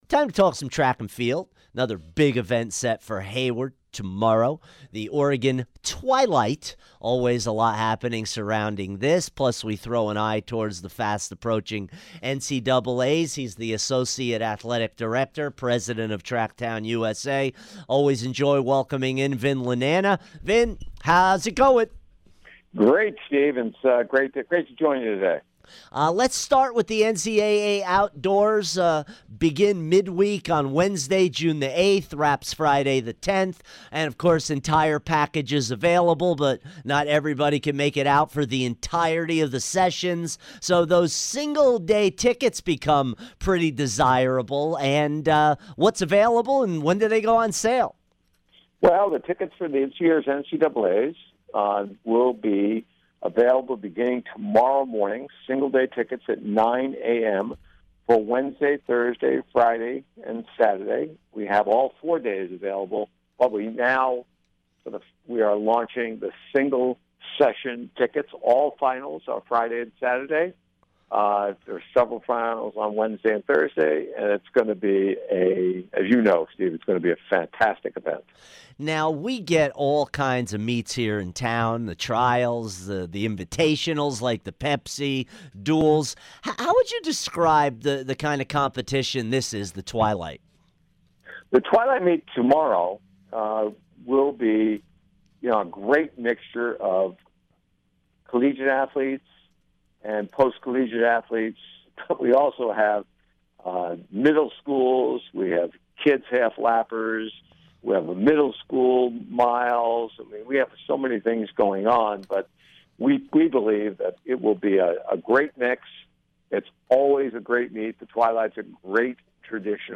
Vin Lananna Interview 5-5-16